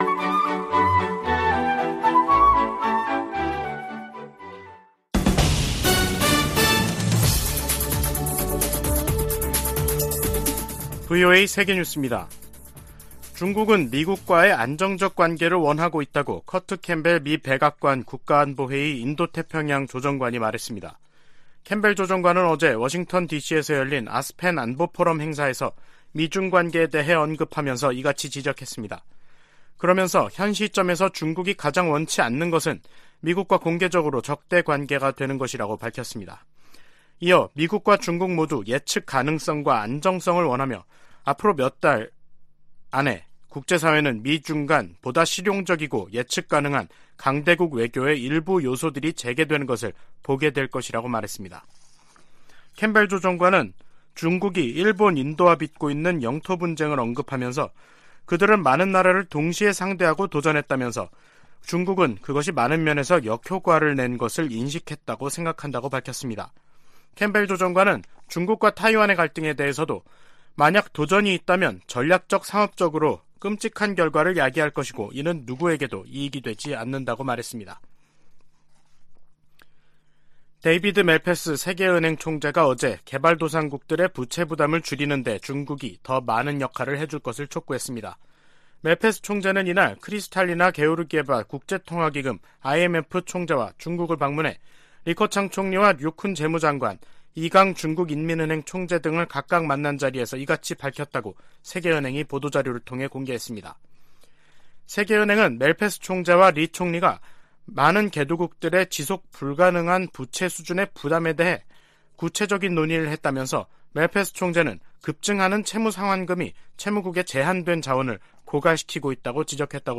세계 뉴스와 함께 미국의 모든 것을 소개하는 '생방송 여기는 워싱턴입니다', 2022년 12월 9일 저녁 방송입니다. 이란 사법부가 반정부 시위 참가자에 대한 첫 사형을 집행했습니다. 미 연방 상원에 이어 하원에서도 동성 간의 결혼을 보호하는 ‘결혼존중법안’이 통과됐습니다.